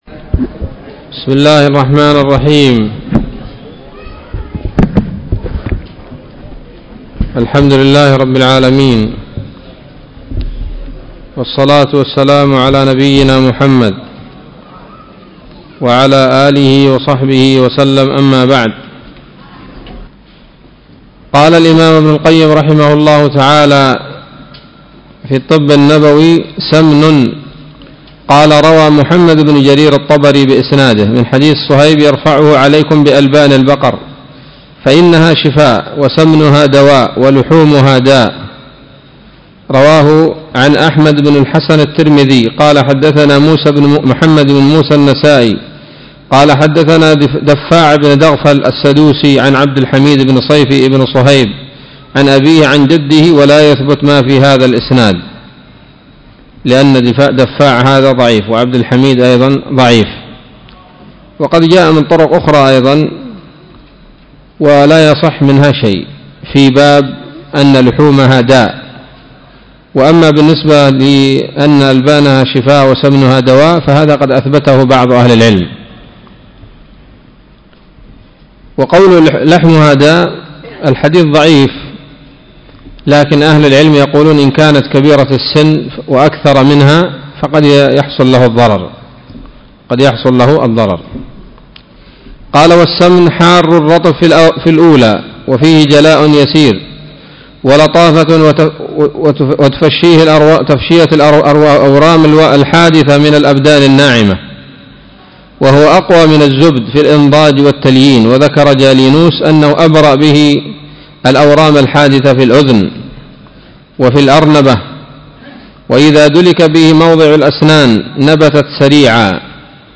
الدرس السابع والثمانون من كتاب الطب النبوي لابن القيم